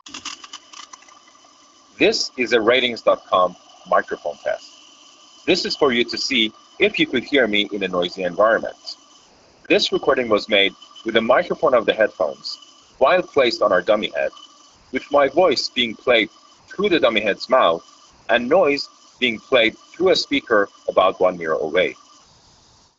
Speech + Pink Noise Audio Sample
Constant background noise is filtered out decently well, and the person on the line can still make out your voice.